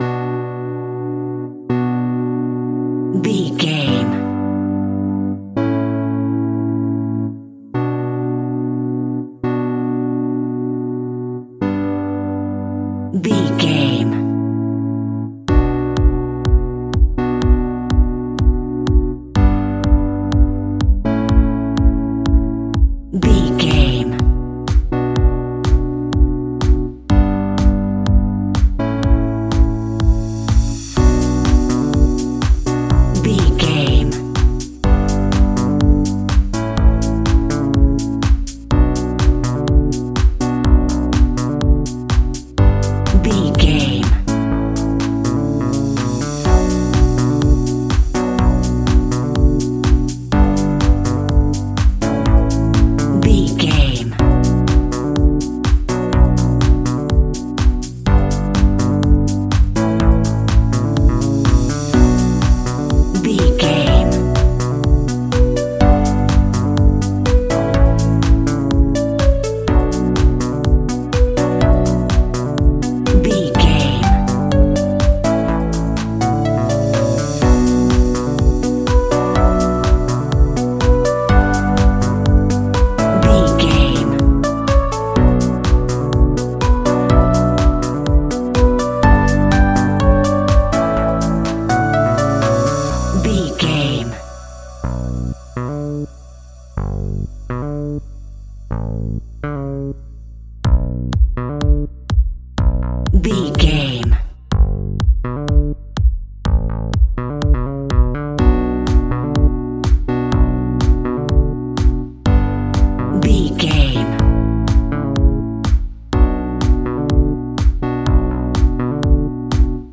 Epic / Action
Fast paced
Aeolian/Minor
groovy
uplifting
driving
energetic
drum machine
synthesiser
electro house
funky house
synth leads
synth bass